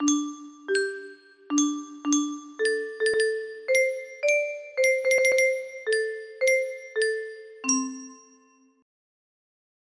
loop music box melody